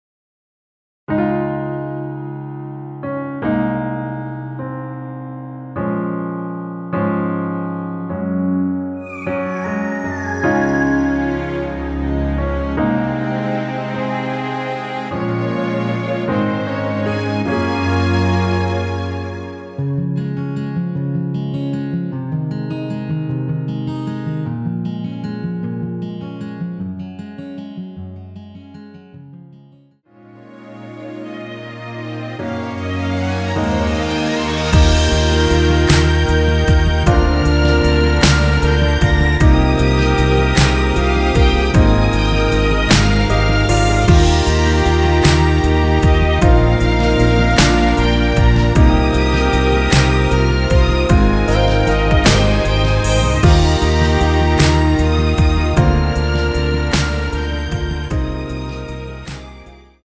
원키에서(-1)내린 MR입니다.
Db
앞부분30초, 뒷부분30초씩 편집해서 올려 드리고 있습니다.